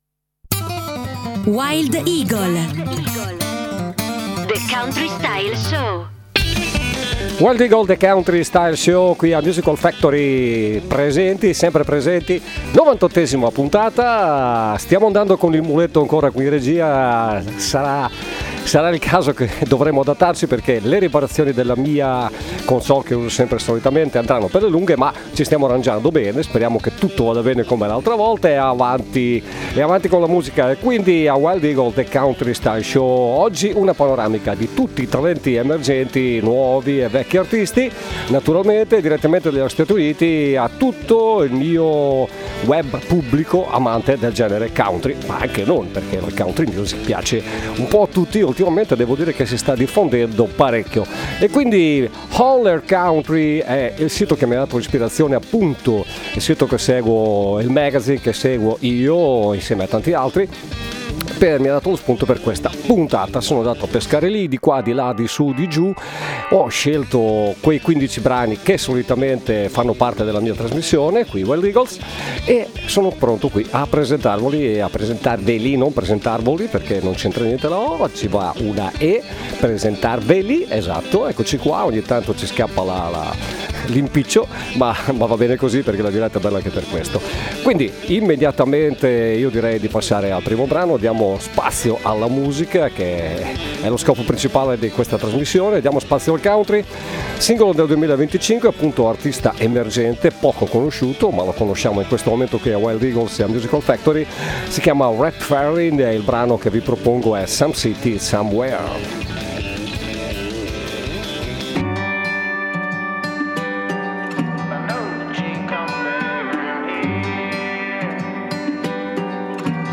genere country